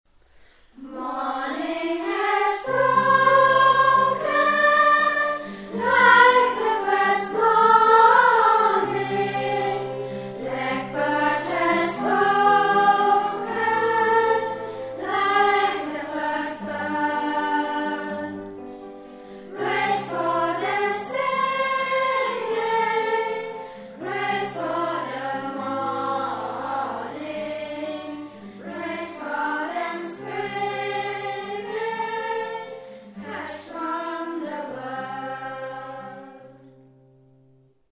Dieses Lied wurde von einer 6. Klasse der Freien Waldorfschule Freiburg St. Georgen eingesungen.
Dieses Lied wurde von einer 6.